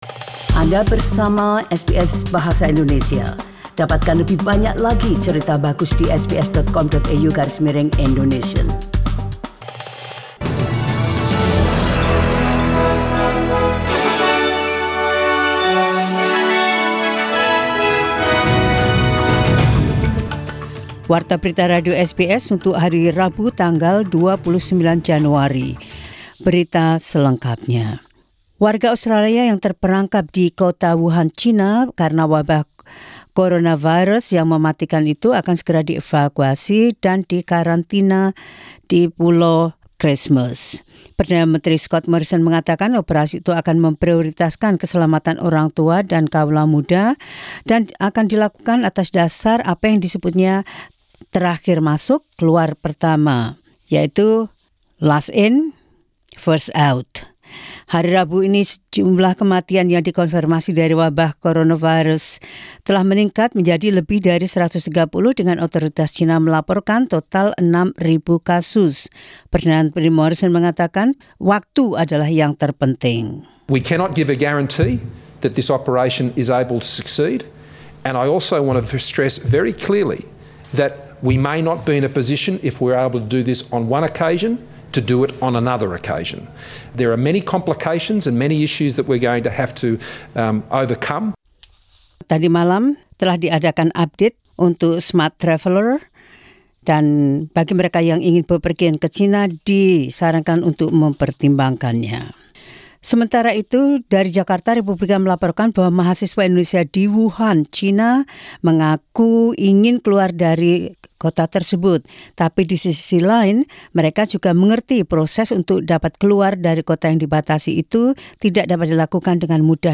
SBS Radio News in Indonesian 29 Jan 2020.